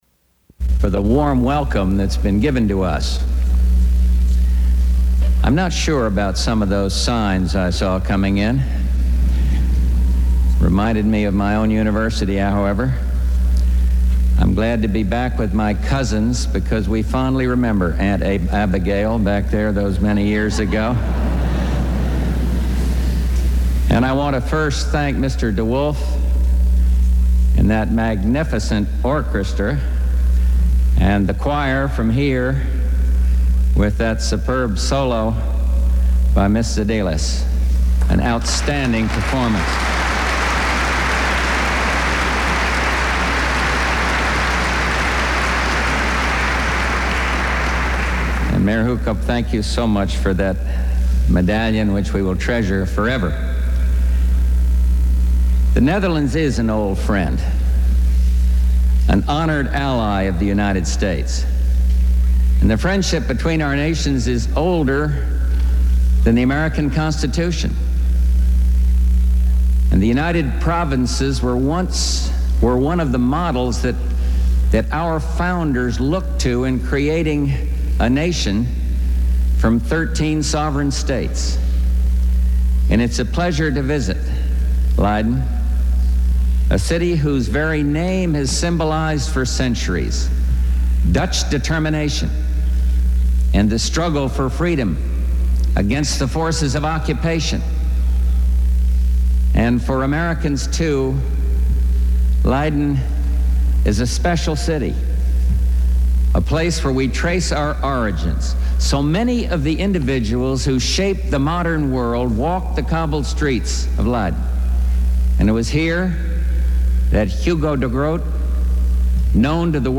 George Bush speaks in the Netherlands
Broadcast on CNN, July 17, 1989.